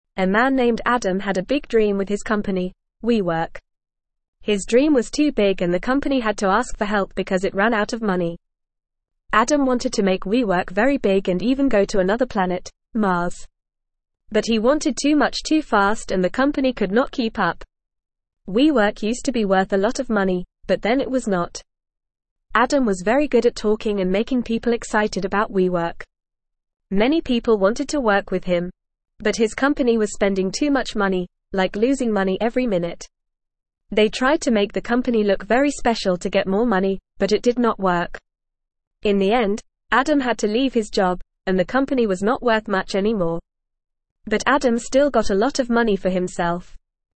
Fast
English-Newsroom-Beginner-FAST-Reading-Adams-Big-Dream-WeWorks-Rise-and-Fall.mp3